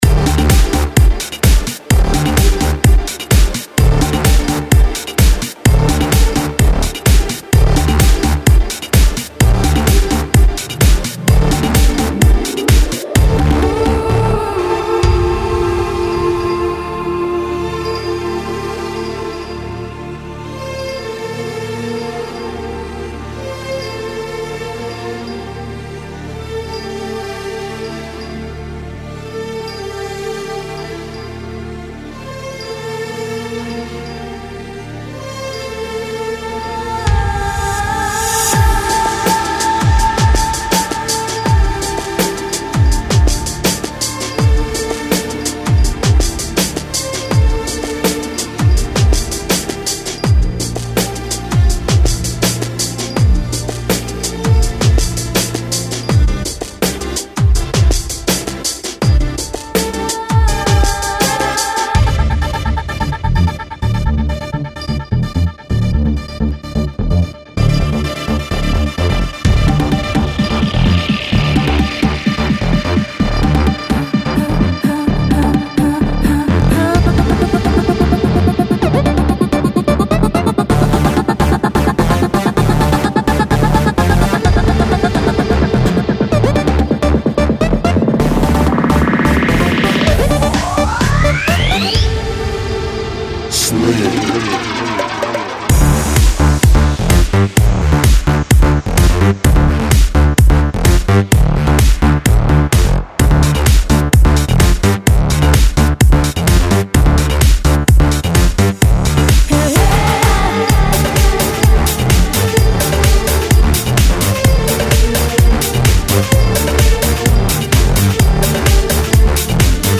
Ребята,что-то новое, спокойное и неописуемое!!!
Жанр:Electro/House